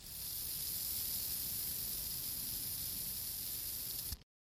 空气清新剂喷剂
描述：一个空气清新剂
标签： 空气 洁净剂 喷雾剂 斯普茨
声道立体声